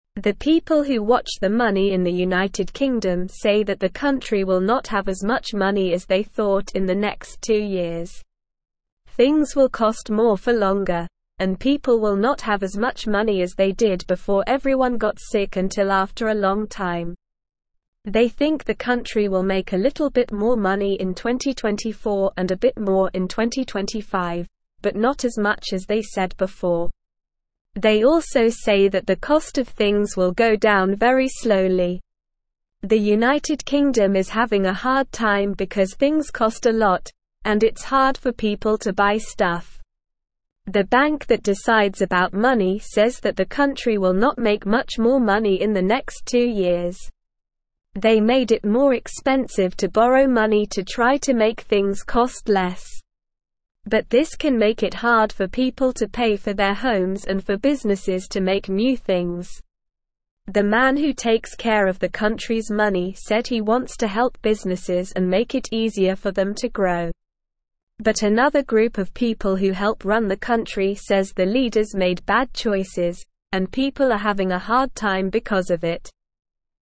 Slow
English-Newsroom-Beginner-SLOW-Reading-UK-Economy-Faces-Challenges-Less-Money-Higher-Costs.mp3